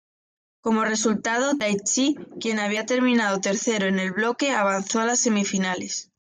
Read more Noun Verb blocar to block, tackle Read more Frequency C1 Hyphenated as blo‧que Pronounced as (IPA) /ˈbloke/ Etymology Borrowed from French bloc In summary Borrowed from French bloc.